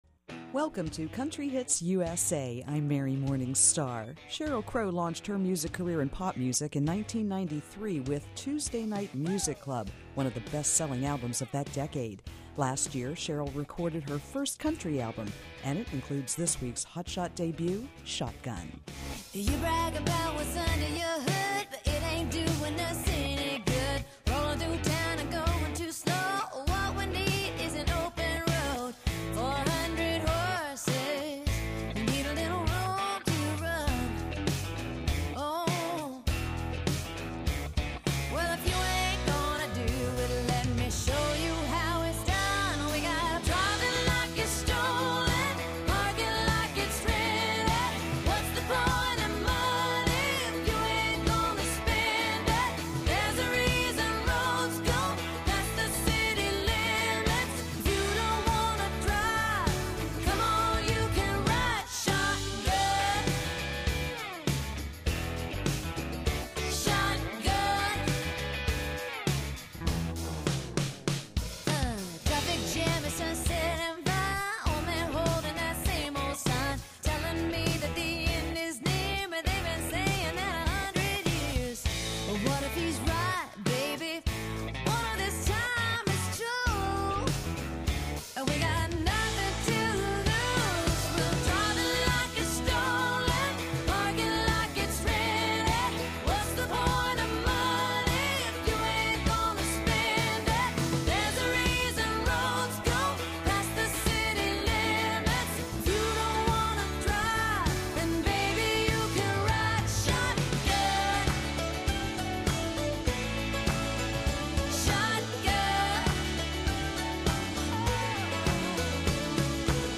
top Country music hits that debuted on Billboard's "Hot Country Singles" chart.